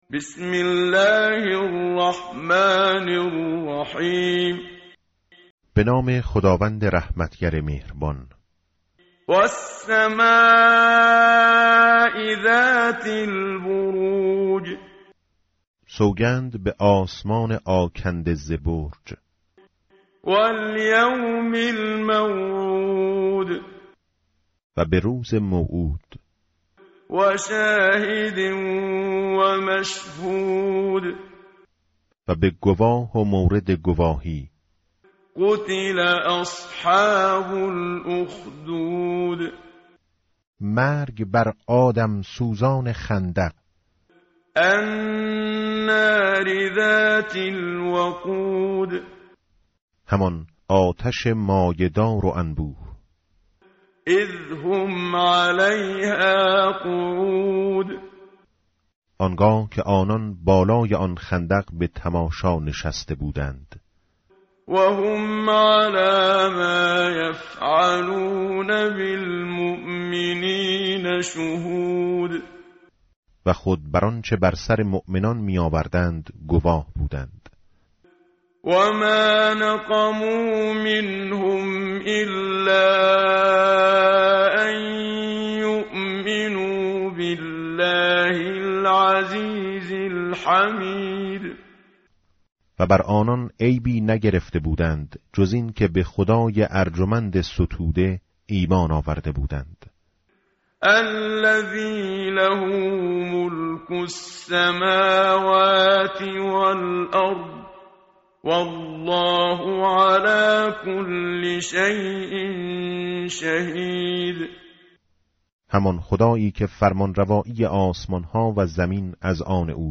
tartil_menshavi va tarjome_Page_590.mp3